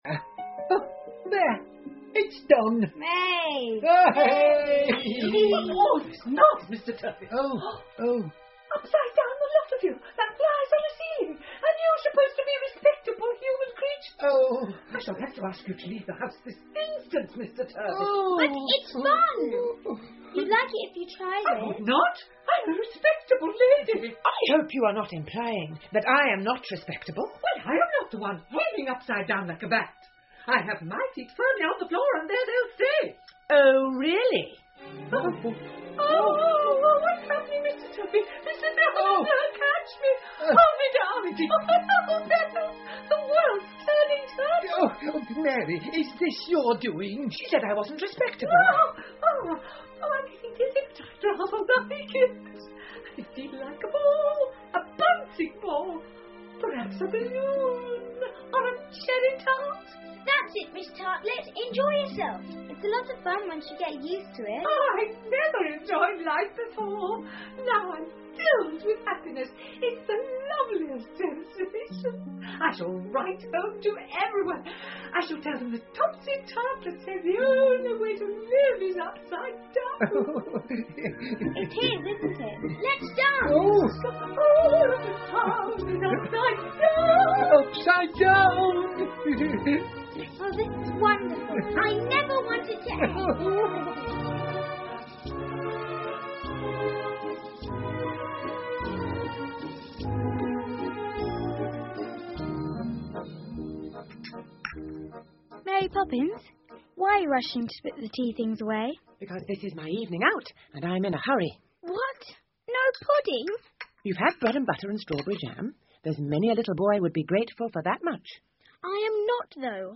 玛丽阿姨归来了 Mary Poppins 儿童英文广播剧 12 听力文件下载—在线英语听力室